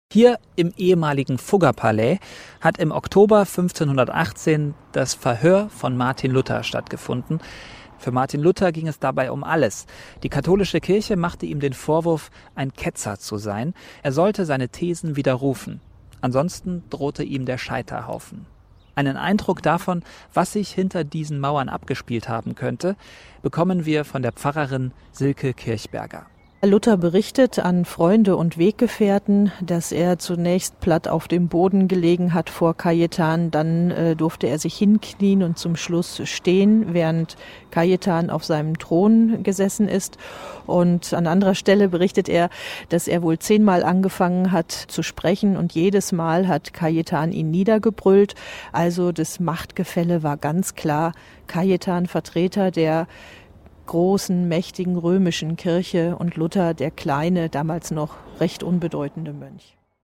Die Lauschtour führt durch die prächtige Altstadt und ihre berühmten evangelischen Kirchen – mit persönlichen Erklärungen von Augsburger Pfarrerinnen und Pfarrern.